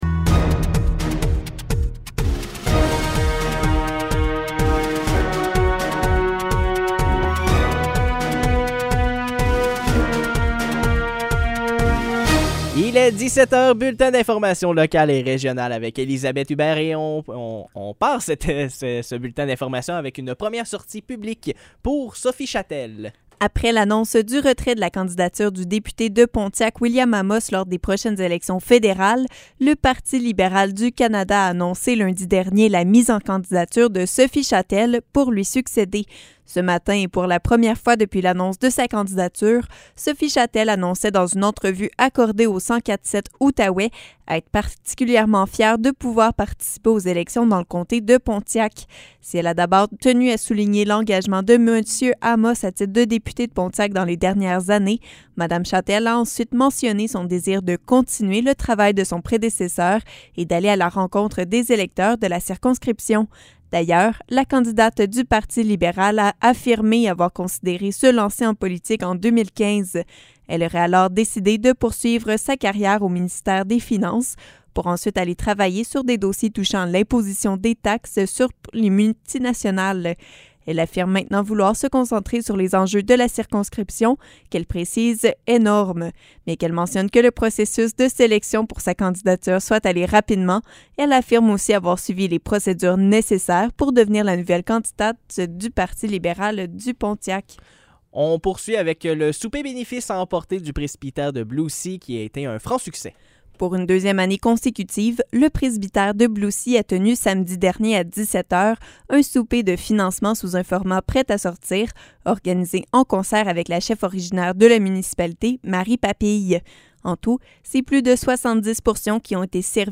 Nouvelles locales - 23 juillet 2021 - 17 h